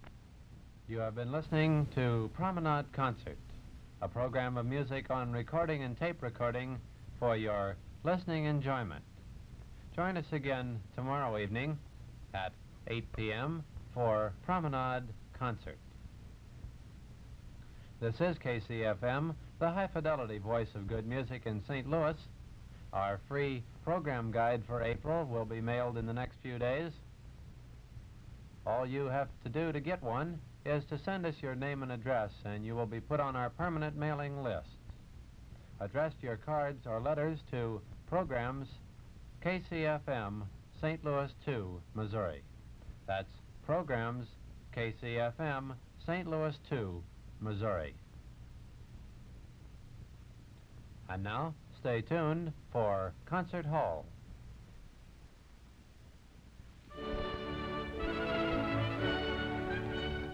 KCFM Unknown Announcer aircheck · St. Louis Media History Archive
Original Format aircheck